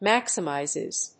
発音記号・読み方
/ˈmæksʌˌmaɪzɪz(米国英語)/
maximizes.mp3